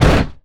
IMPACT_Generic_08_mono.wav